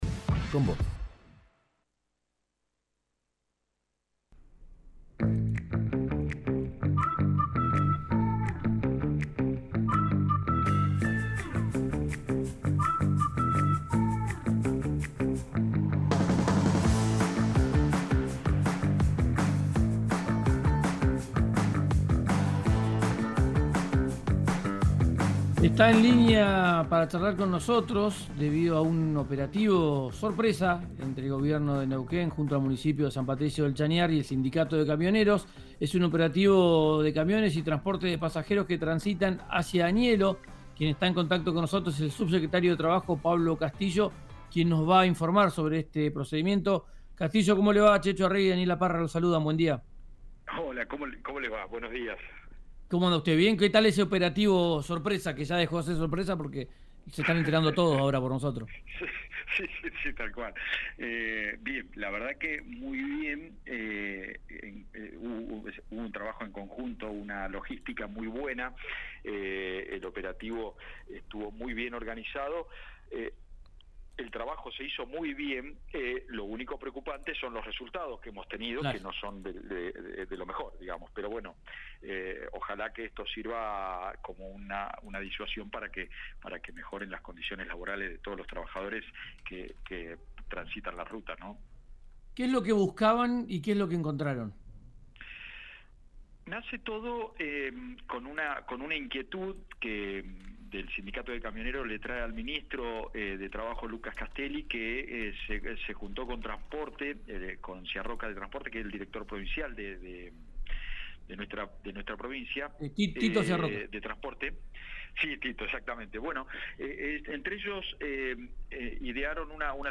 Escuchá al subsecretario de Trabajo, Pablo Castillo, EN RÍO NEGRO RADIO